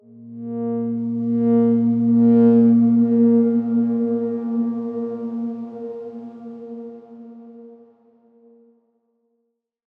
X_Darkswarm-A#2-pp.wav